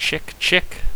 Adding some sound effects
reload.ogg